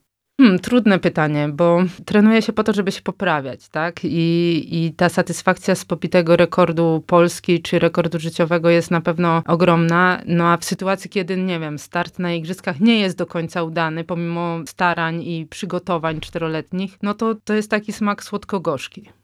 Które uczucie smakuje lepiej: medal ważnej imprezy czy świadomość bycia najszybszą pływaczką w historii kraju? Odpowiada Alicja Tchórz.
Cała rozmowa z Alicją Tchórz w audycji „Sportowy kwadrans” dziś o 15:30!